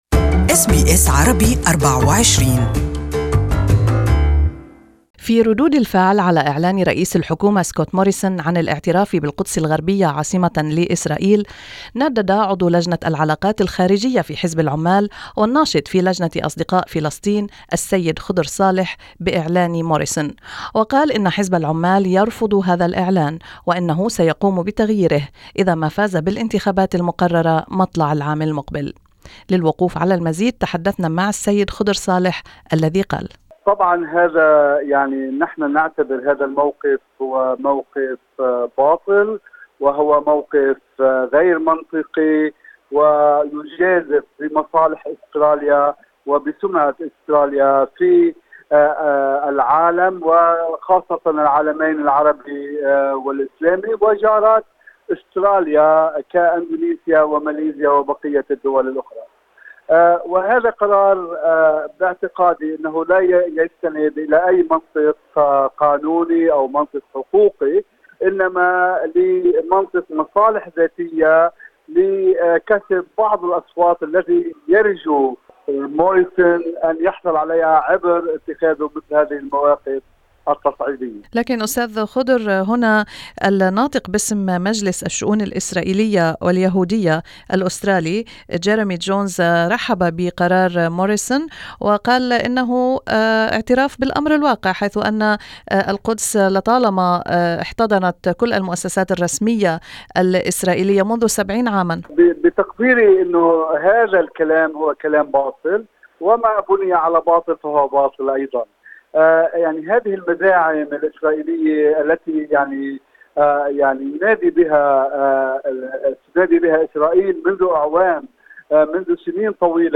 More in the interview in Arabic.